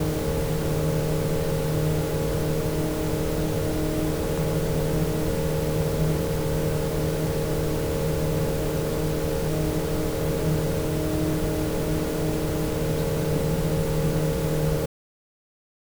Звук не очень громкий, раздается в основном по стене, соединяющей два подъезда.
Попытался записать звук, вплотную прижав микрофон к стене и закрыв звукопоглощающим материалом.
Частоты немного хаотически гуляют. Нижний пик 152-160Гц, остальные примерно в таком же диапазоне около 10 гц.
Вероятно, это звук какого-то мотора или чего-то другого вращающегося.